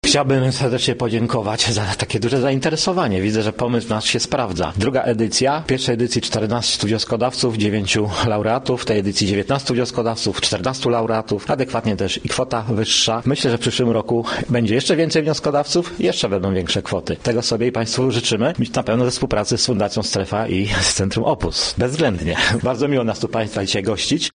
– mówił wójt gminy Mokrsko, Zbigniew Dąbrowski.